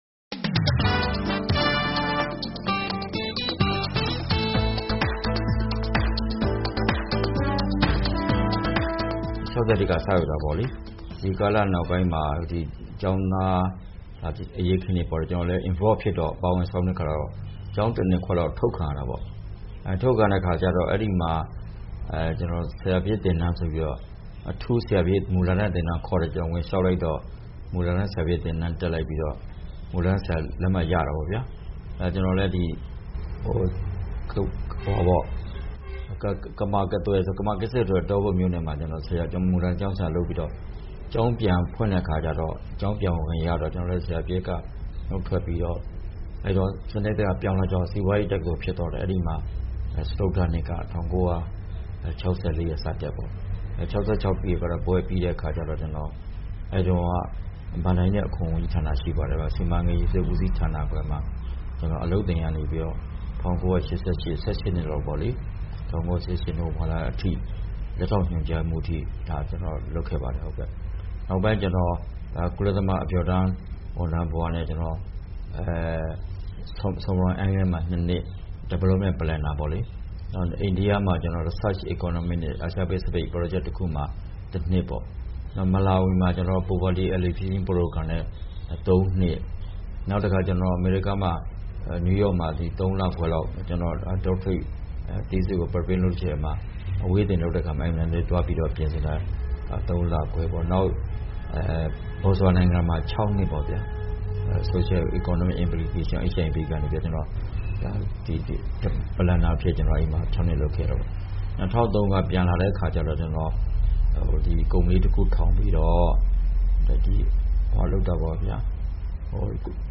Interview with Minister Dr. Than Myint